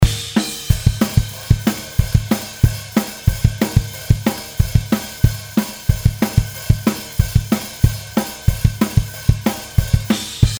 わかりやすいのは、キックの音がぼや〜んっと鳴っていますよね。
ドラムの音がロックっぽいのでもっとソリッドな雰囲気の方がいいかなと思いました。